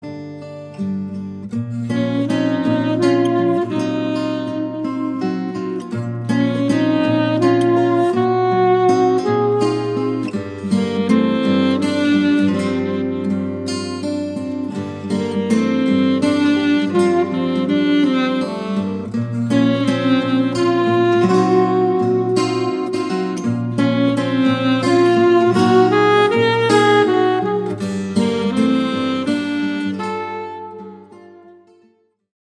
tango caboclo